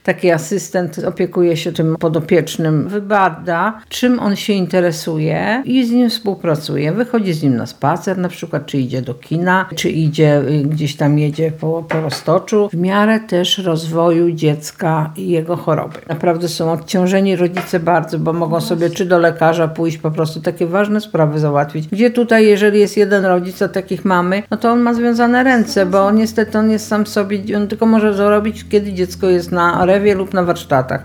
Wychodzi z nim na spacer czy idzie do kina – mówi w rozmowie z Radiem Lublin.